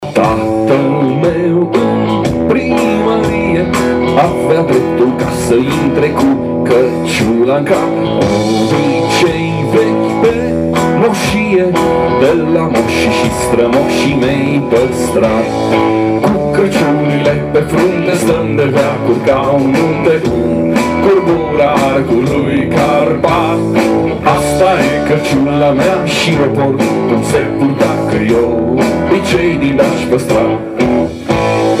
chitară bas
clape
voce și chitară.